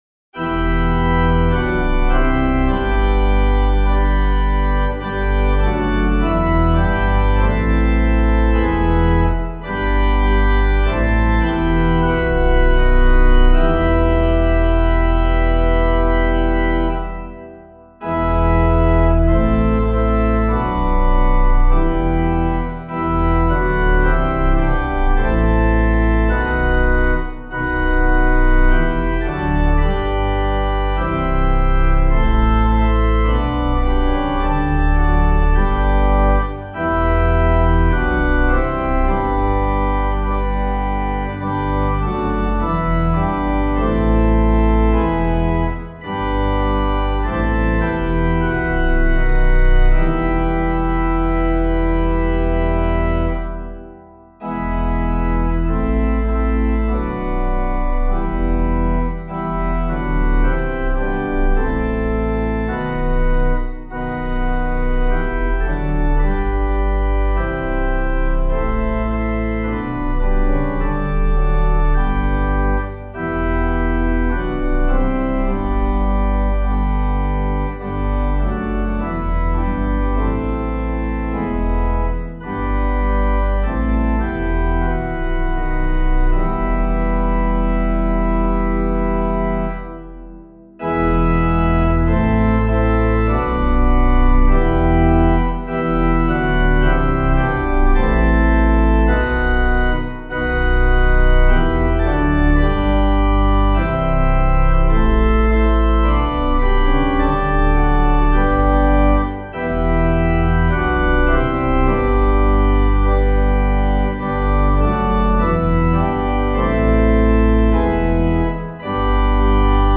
Hymn of the Day:  Friday of the Passion of the Lord